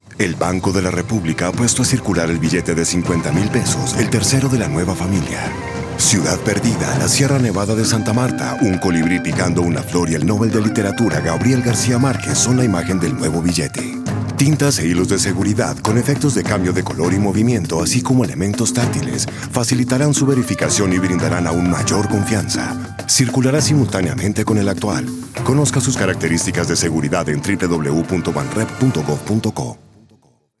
Cuña radial